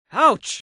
Game: BattleBlock Theater Narrator Voices (Xbox 360, Windows) (2013)